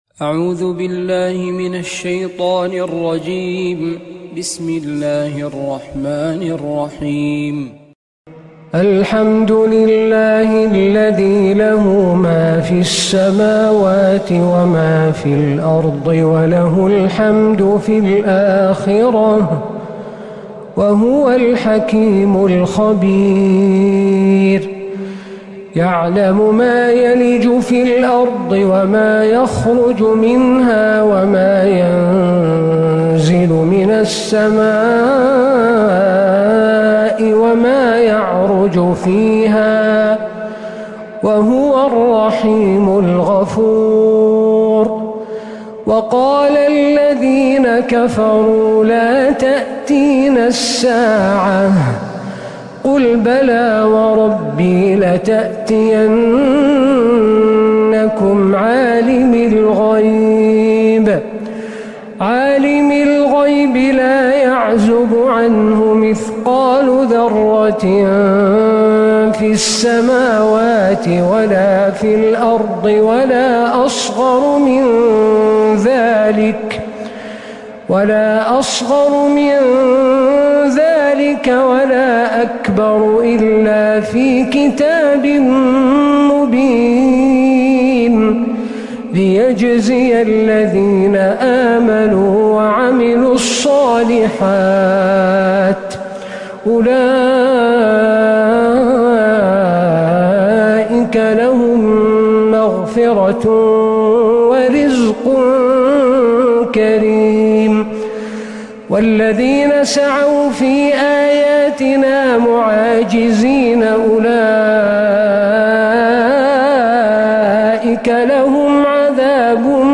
سورة سبأ كاملة من الحرم النبوي